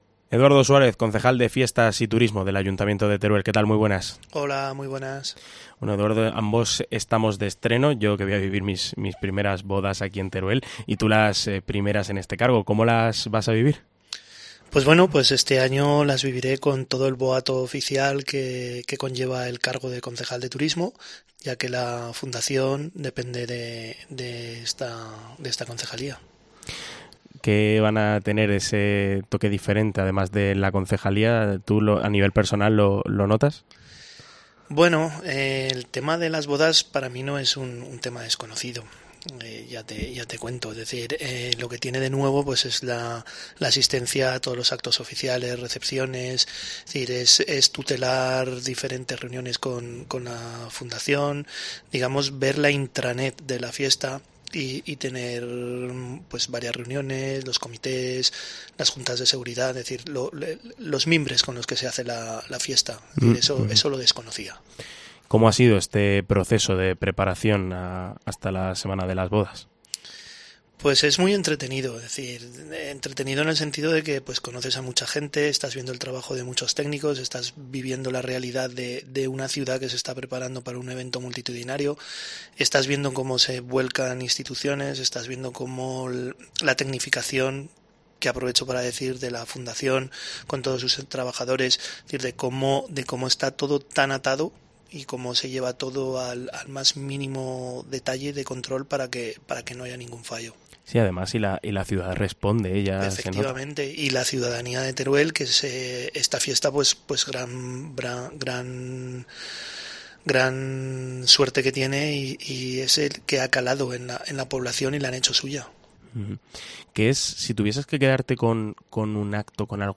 Especial Bodas de Isabel: Entrevista a Eduardo Suárez, concejal de Turismo y Fiestas de Teruel